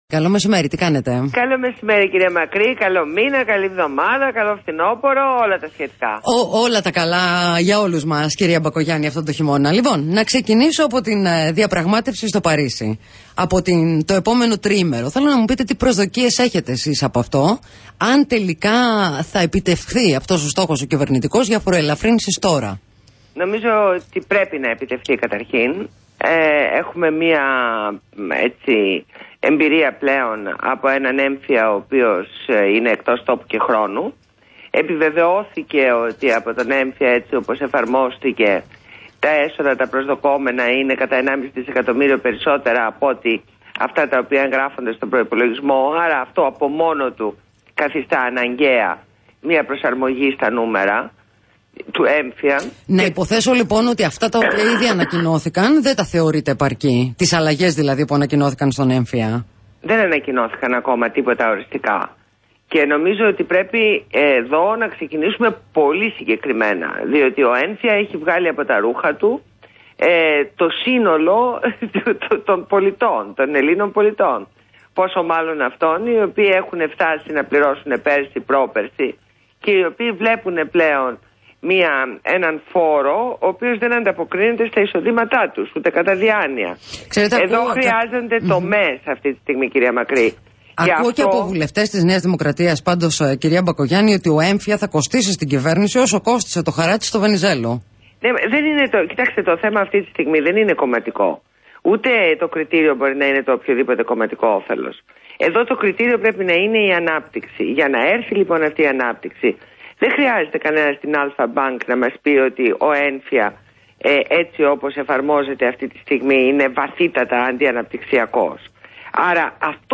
Ακούστε τη ραδιοφωνική συνέντευξη εδώ: